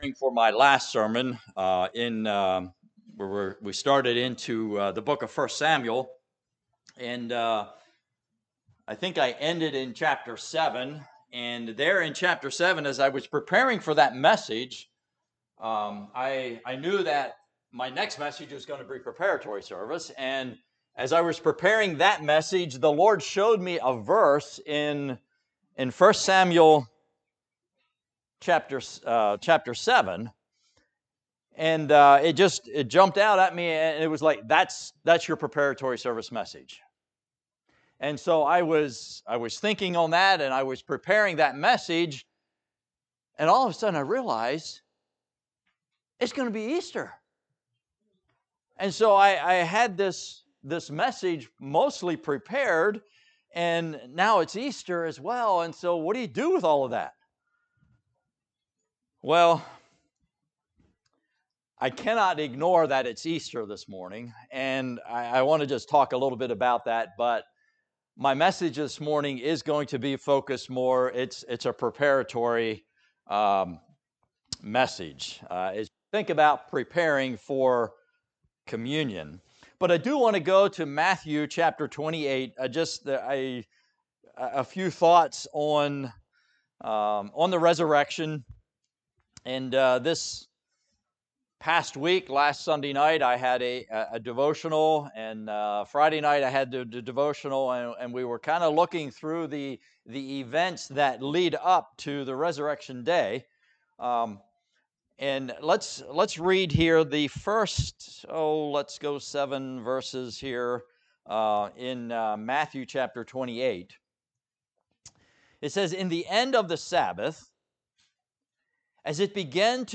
Preparatory Service - Word of Life Mennonite Fellowship
Listen to sermon recordings from Word of Life Mennonite Fellowship.